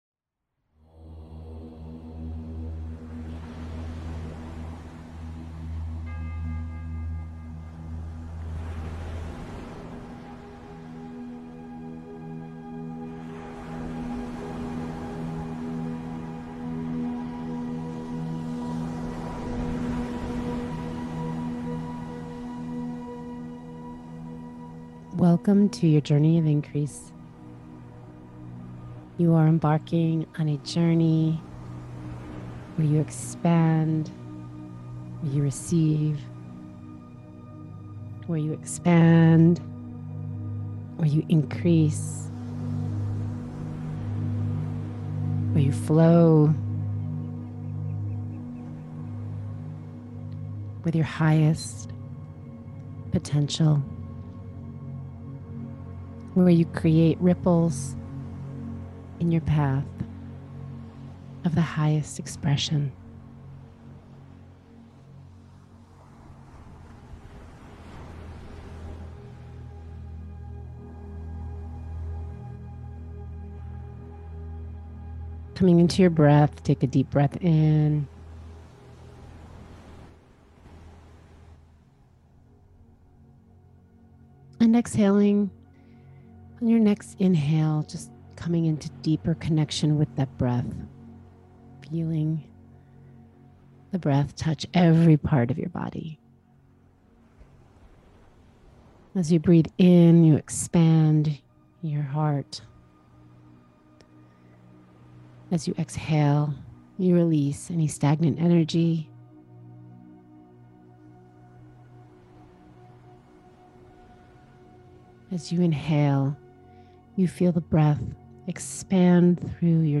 Visualization-for-Opt-In-Page-2.mp3